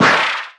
dazed_1.ogg